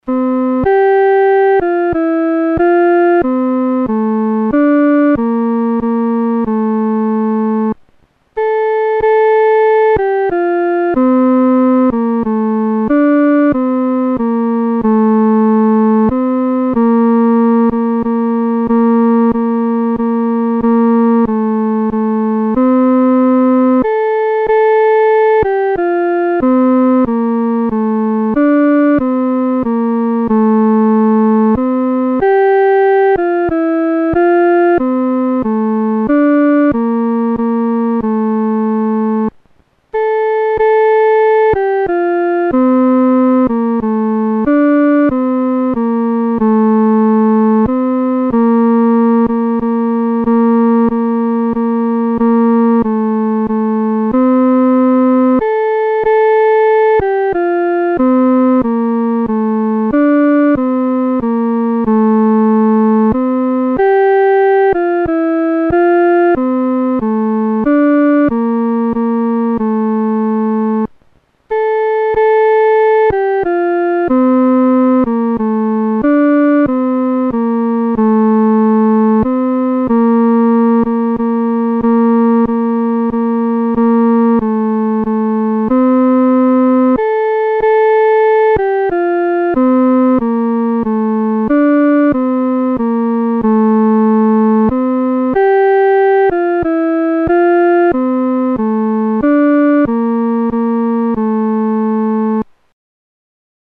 独奏（第二声）
在马槽里-独奏（第二声）.mp3